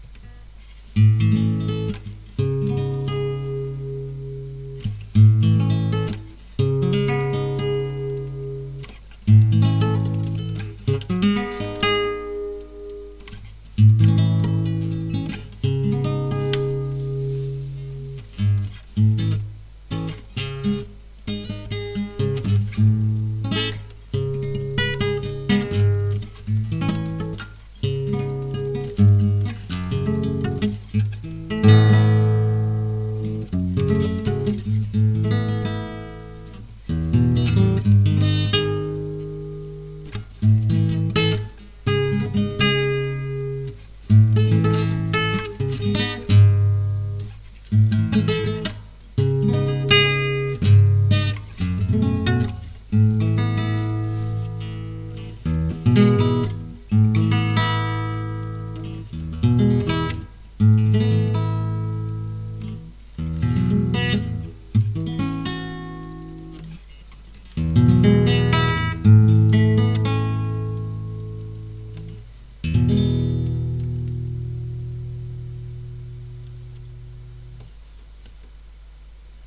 描述：雅马哈C40尼龙吉他录音。
Tag: 古典 尼龙 声学 吉他